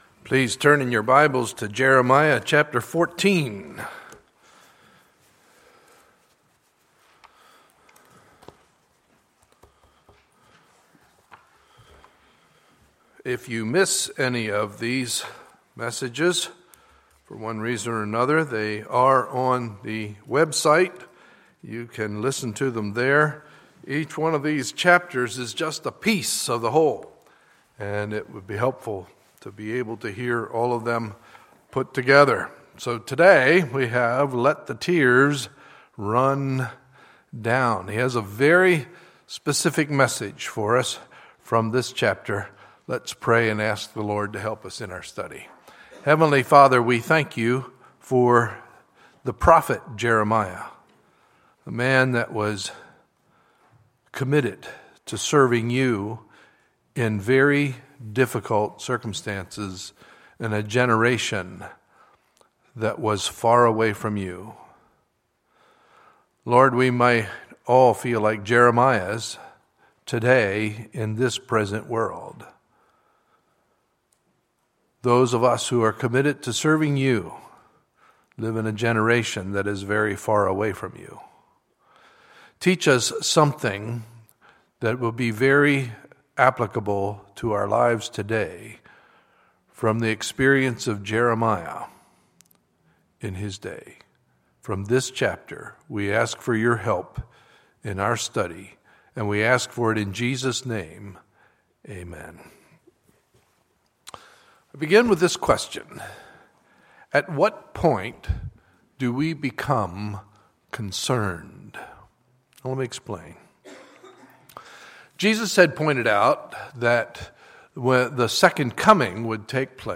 Sunday, April 26, 2015 – Sunday Morning Service